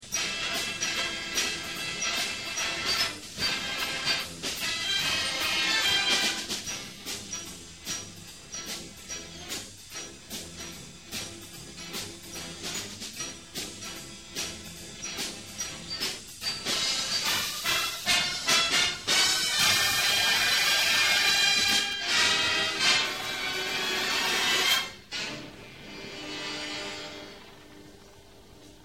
Die Stücke wurden während des Konzerts 2003 live (unplugged) aufgenommen.